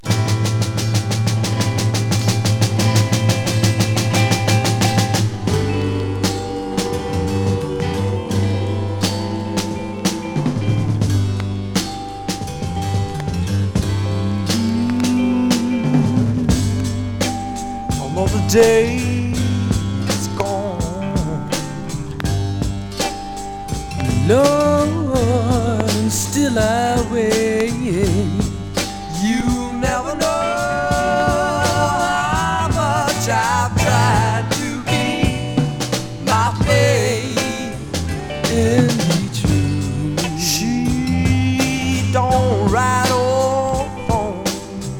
サウンドは荒々しく、まさにダイナマイト。
Rock, Garage, Psychedelic　USA　12inchレコード　33rpm　Stereo
ジャケ擦れ汚れ裂けテープ補修　盤擦れキズ　試聴2で確認ください